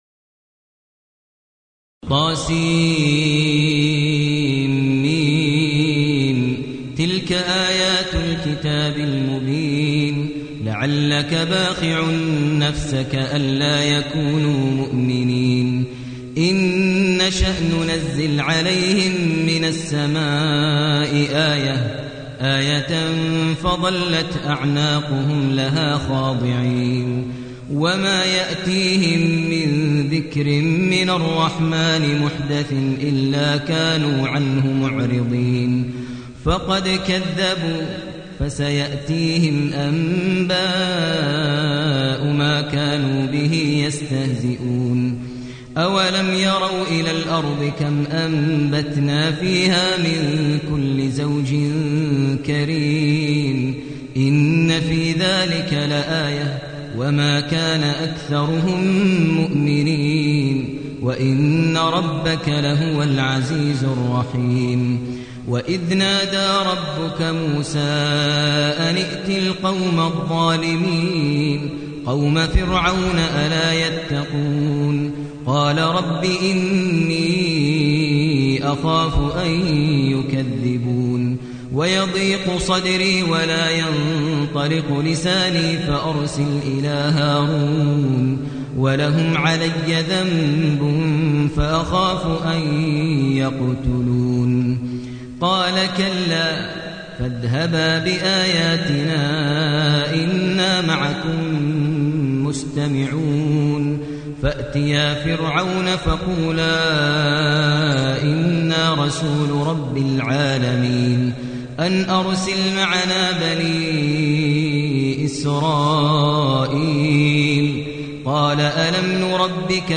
المكان: المسجد النبوي الشيخ: فضيلة الشيخ ماهر المعيقلي فضيلة الشيخ ماهر المعيقلي الشعراء